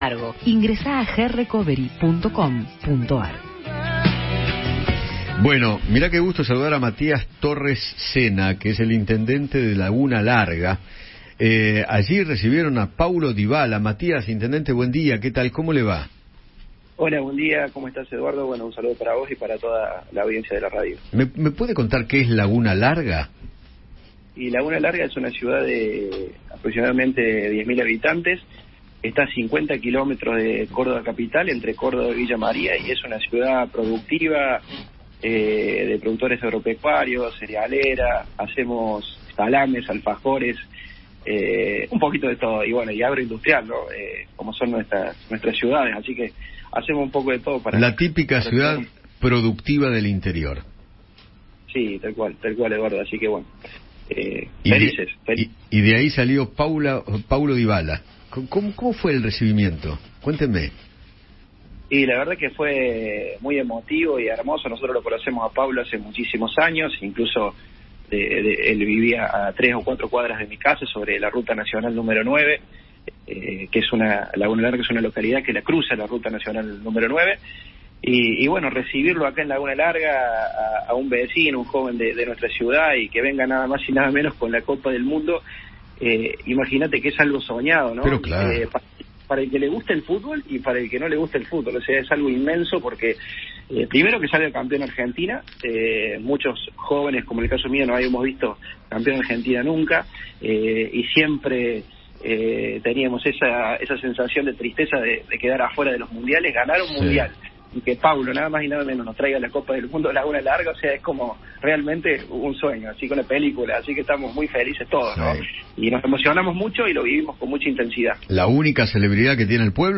Matías Torres Cena, intendente de Laguna Larga, de donde es oriundo Paulo Dybala, habló con Eduardo Feinmann sobre el recibimiento que le hicieron al jugador de la Selección en dicha localidad de Córdoba.